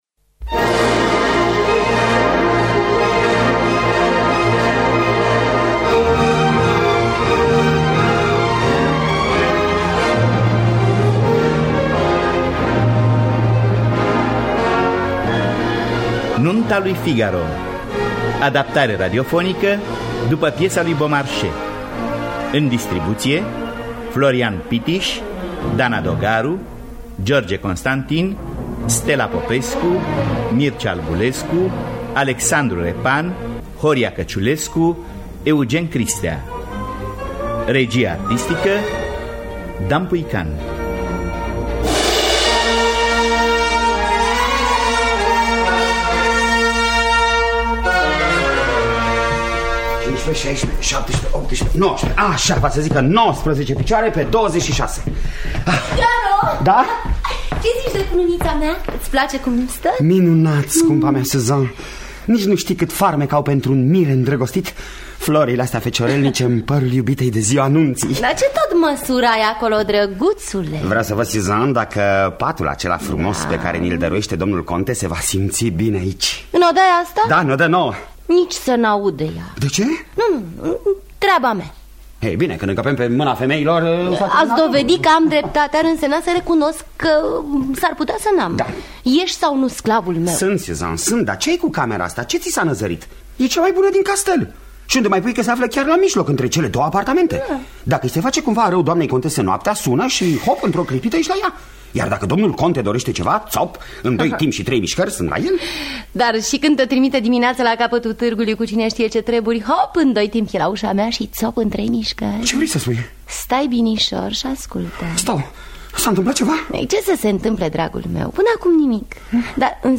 “Nunta lui Figaro” de Pierre-Augustin Caron de Beaumarchais. Adaptarea radiofonică